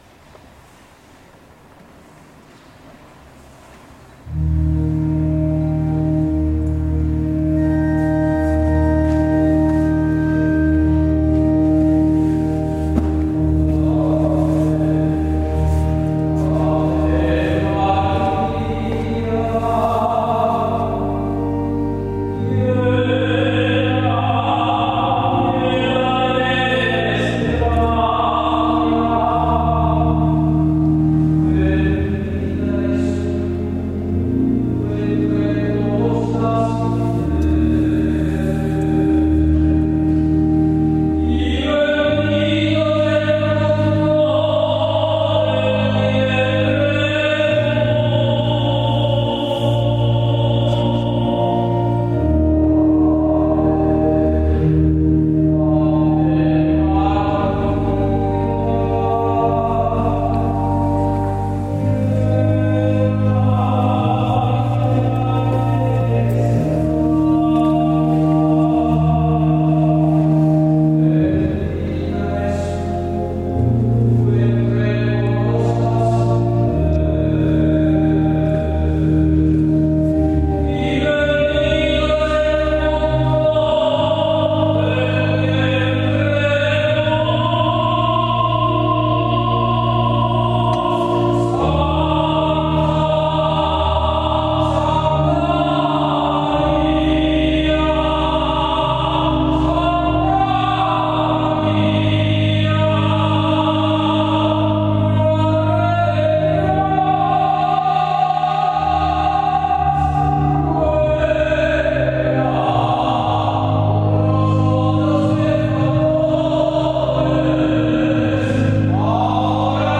20 settembre concerto NOTE DI SALUTO